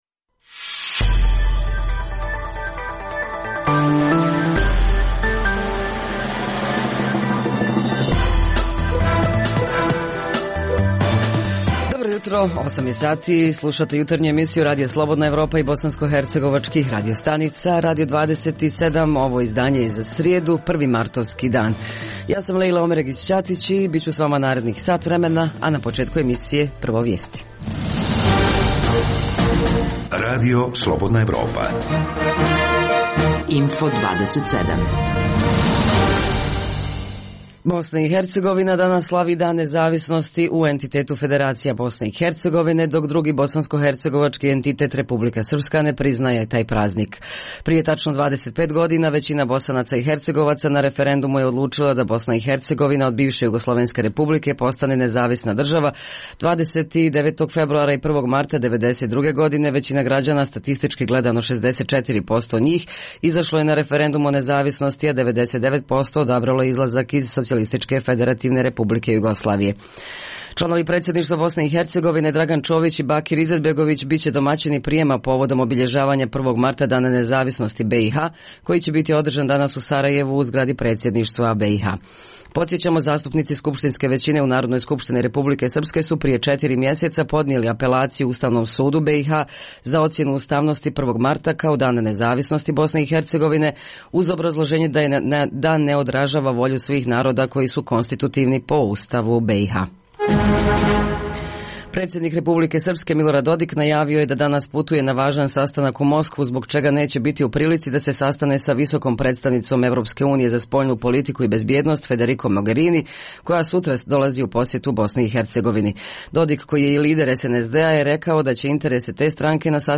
- O obrazovanju govorimo u aktuelnostima: Visoka zdravstvena škola u Bihaću uskoro bi trebala prerasti u Fakultet zdravstvenih studija, a srednjoškolcima u Vlasenici koji se školuju za zanimanje šumarski tehničar, biće omogućena savremena praktična nastava Osim toga, u prvom dijelu emisije čućemo i građane Banja Luke, Tuzle i Sarajeva kojima je preko glave neprestanih tenzija koje stvaraju bh. političari – ako se uopšte više tako mogu i nazvati.